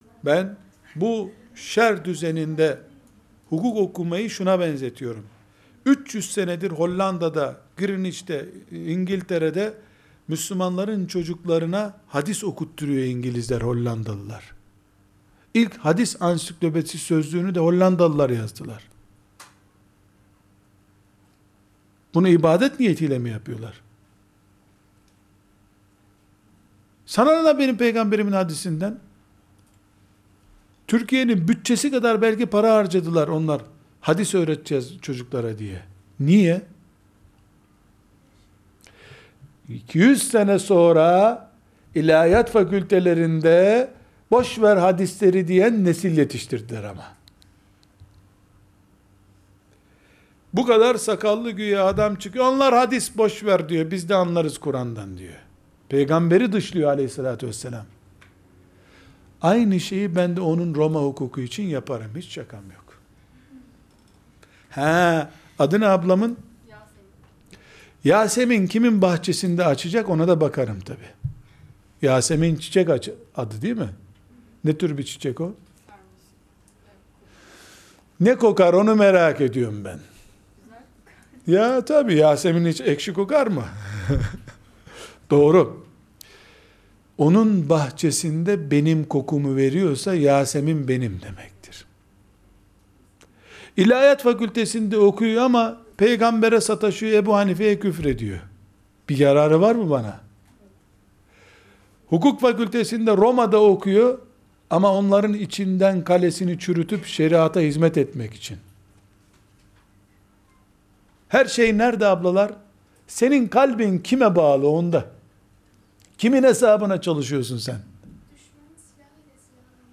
2. Soru & Cevap